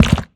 02_bomb_holdup_fail_01.wav